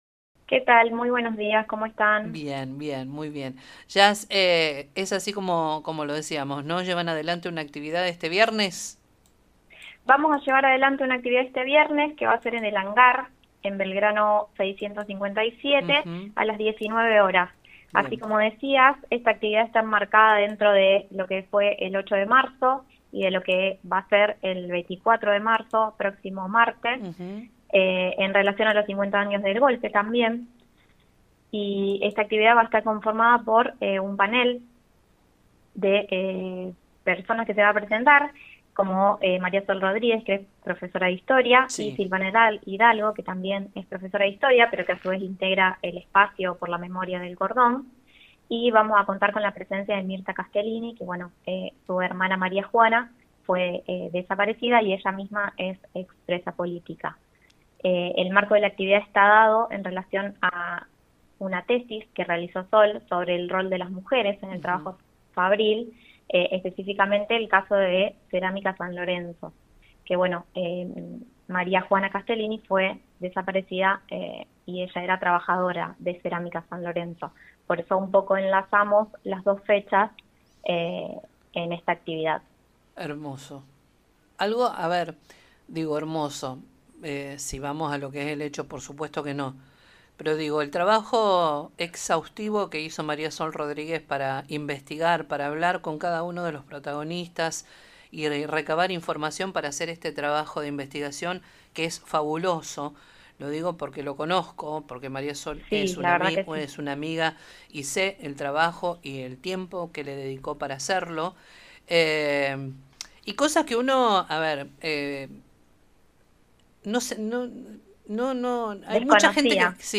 Memorias del Cordón” en contacto con el programa “Con Voz” de Radio 102.9.